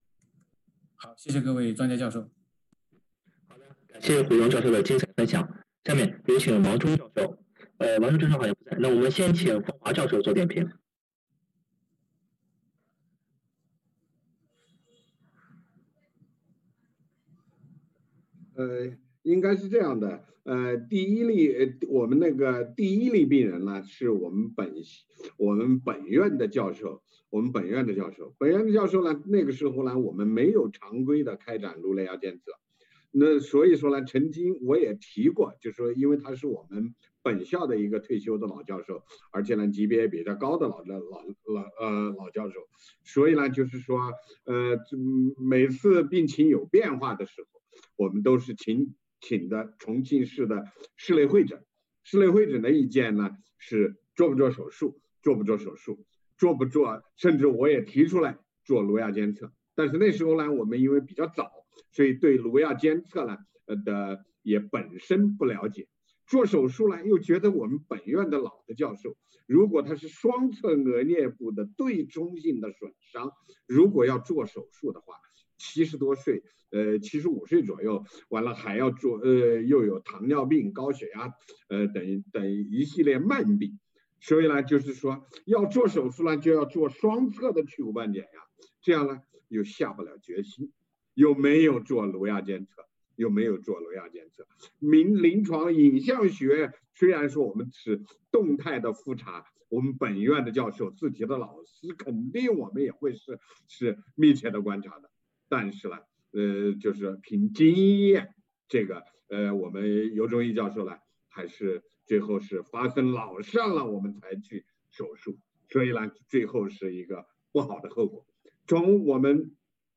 精彩点评